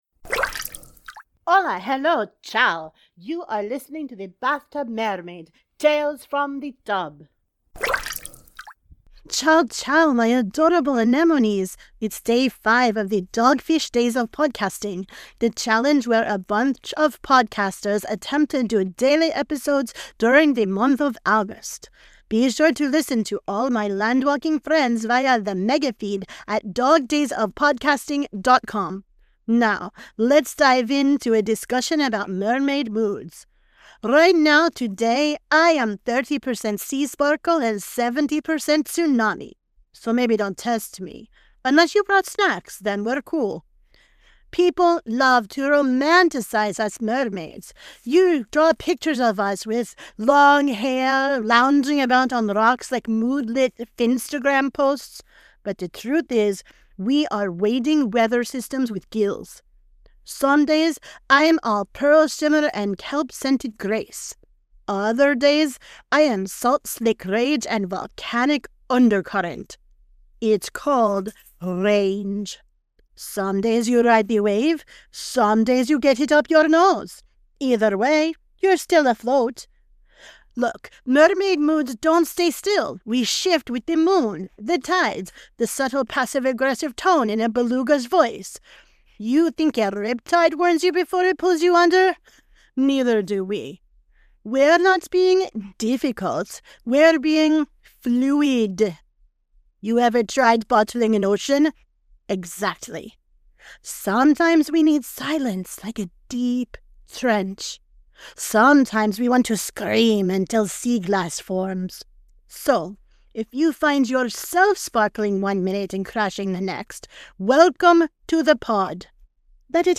• Sound Effects are from Freesound.